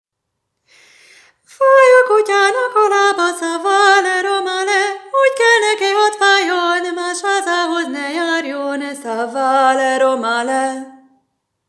Az ének-zenei nevelés óvodai módszertanának e területén különösen nagy a hiányosság, s ezt az elmaradást igyekszik pótolni a tanulmány egy autentikus vokális cigány népzenei anyag gyűjteményével.